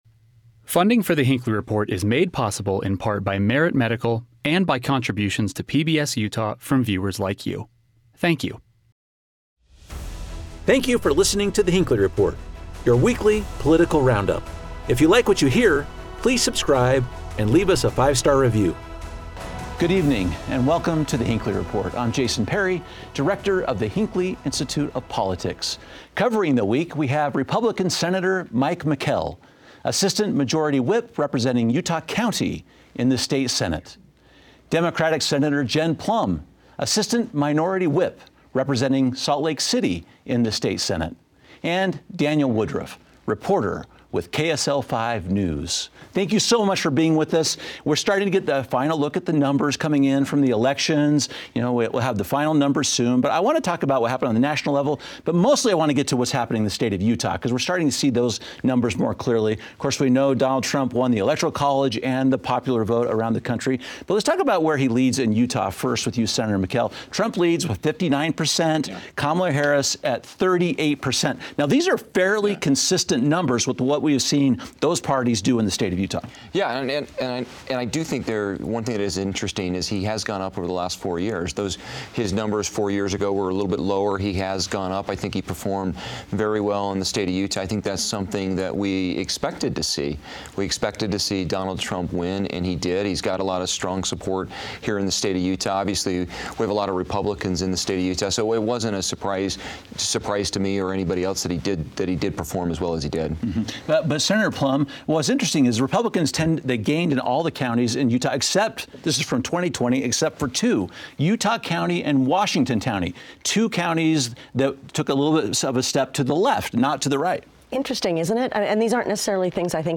New polling suggests Republicans and Democrats across the country had vastly different priorities when they cast their ballots. Our panel examines how those priorities translated to wins and losses, and what they suggest about the state of politics in the United States.